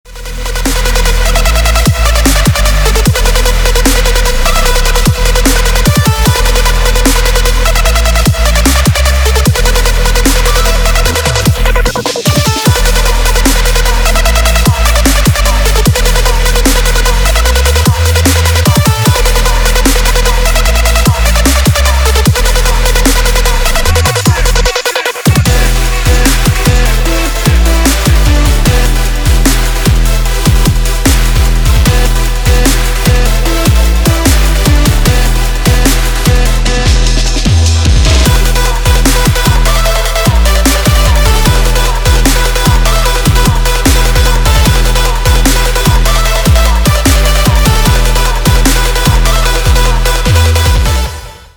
Клубные ремиксы на мобилу
Клубные рингтоны